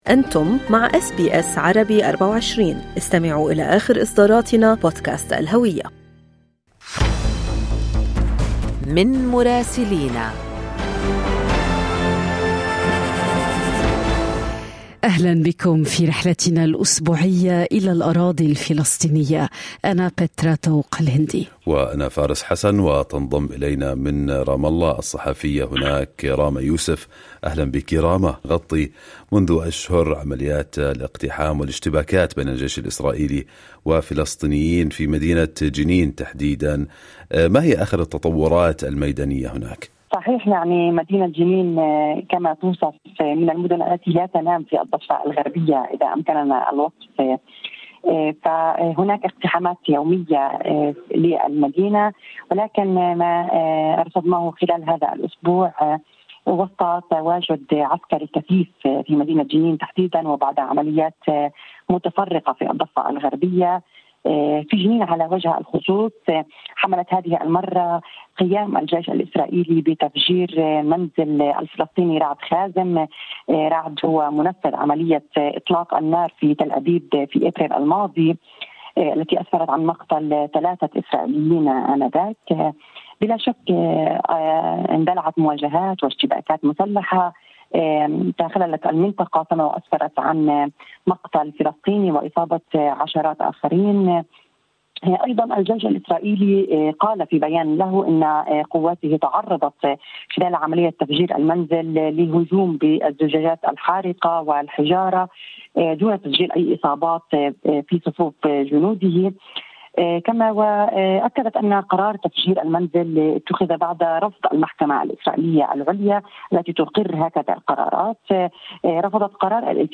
يمكنكم الاستماع إلى التقرير الصوتي من رام الله بالضغط على التسجيل الصوتي أعلاه.